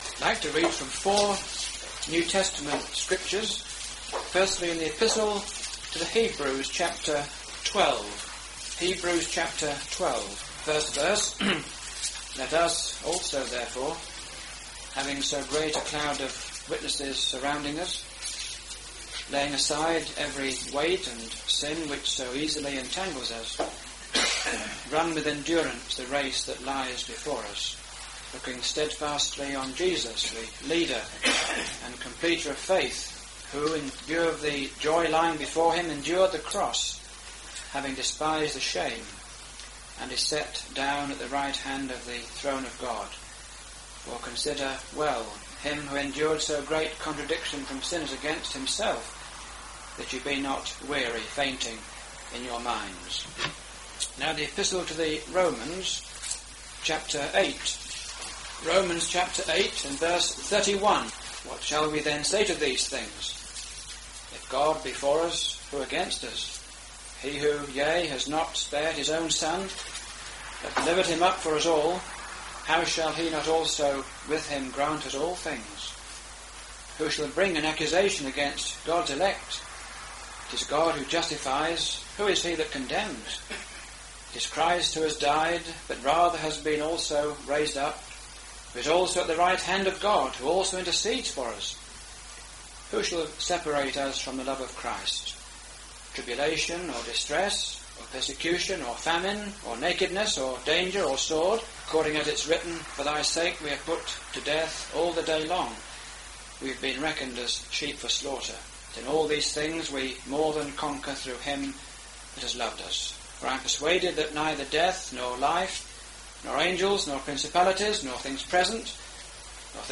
Jesus is risen and ascended and is seated at the right hand of God. In this address, you hear of where the Lord Jesus is now.